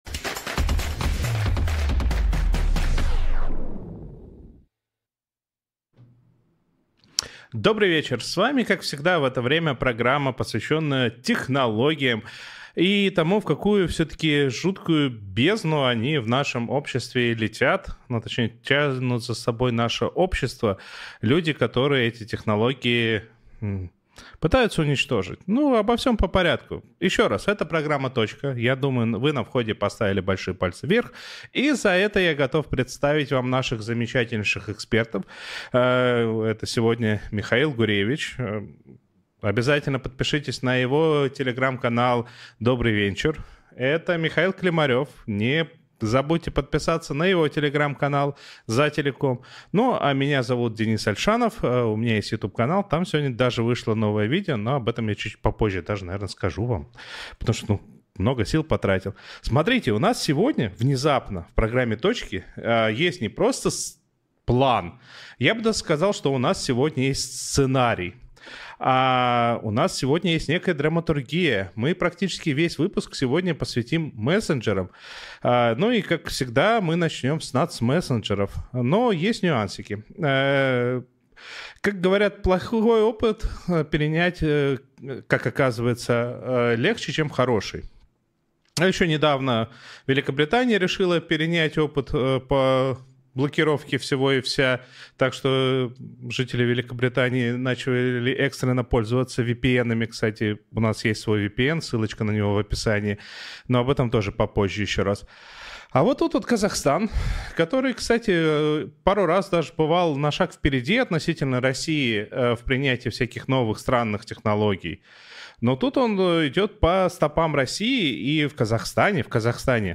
говорит с экспертами про интернет и технологии в нашей жизни